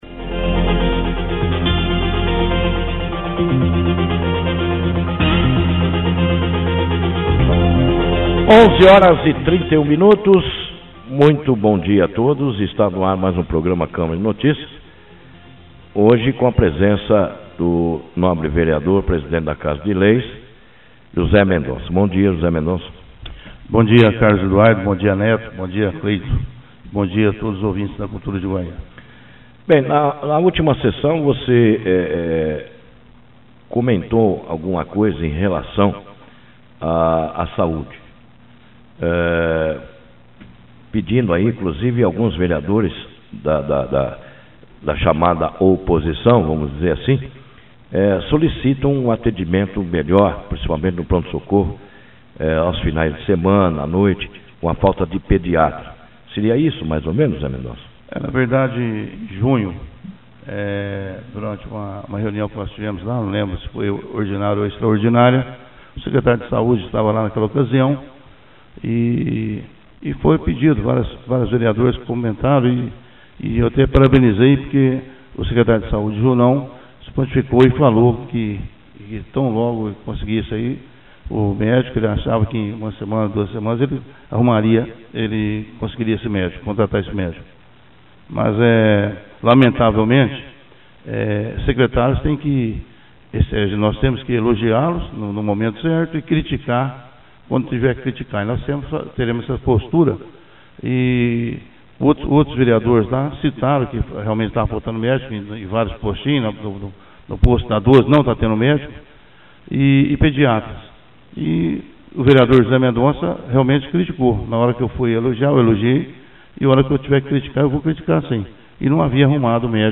Presidente da Câmara na rádio Cultura AM – Câmara Municipal de Guaíra-SP